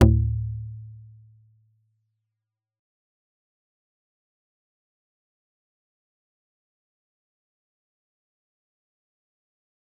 G_Kalimba-D2-f.wav